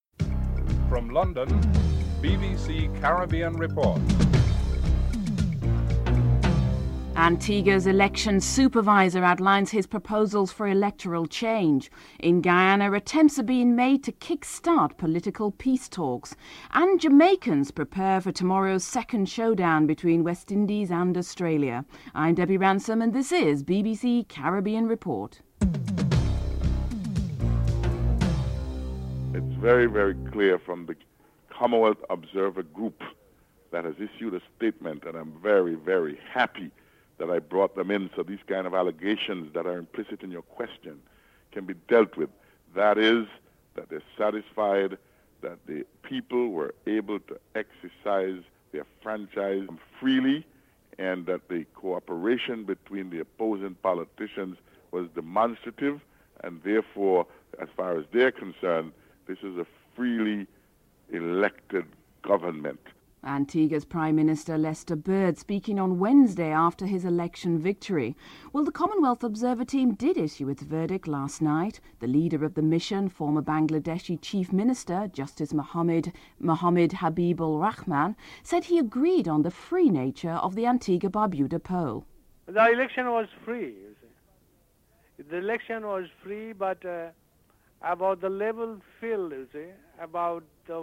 Antigua Prime Minister Lester Bird comments on the Observer Team report.